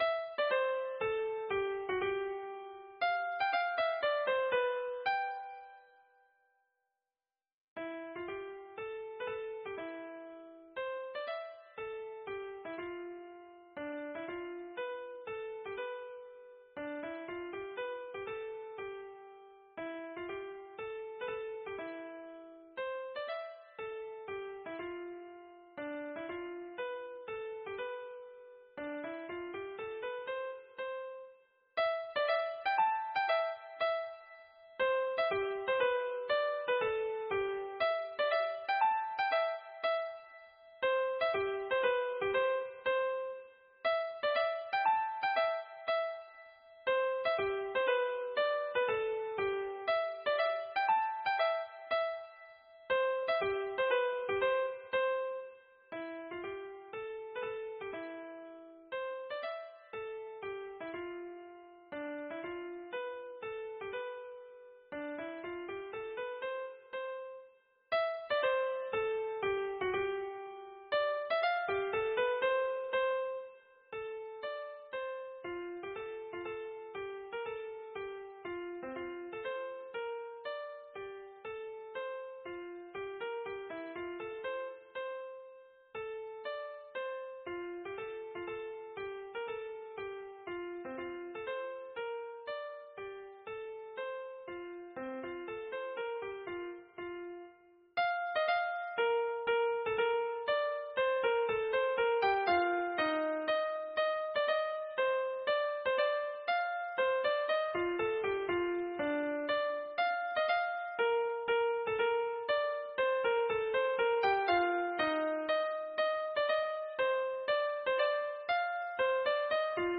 Mazurka - Pensée sentimentale